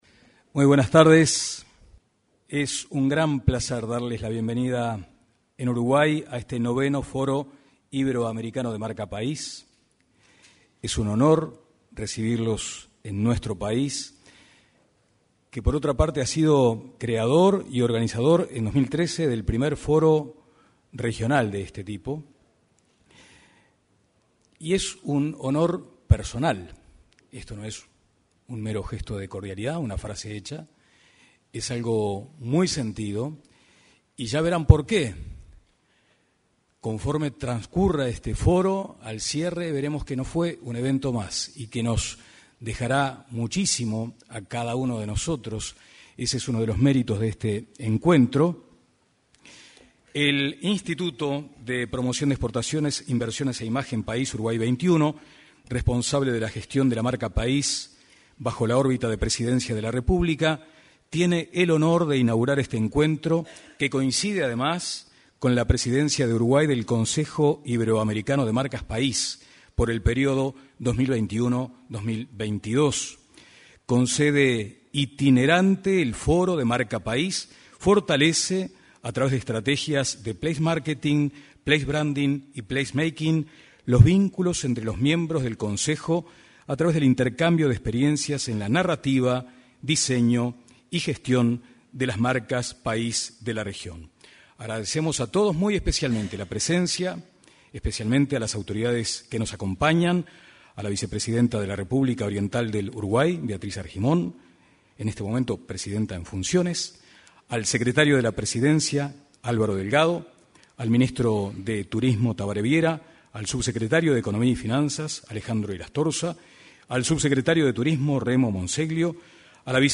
Foro Iberoamericano de Marca País 27/10/2022 Compartir Facebook X Copiar enlace WhatsApp LinkedIn Se desarrolló en el auditorio de la Torre Ejecutiva la apertura del Foro Iberoamericano de Marca País. En la oportunidad, se expresó la vicepresidenta de la República, Beatriz Argimón.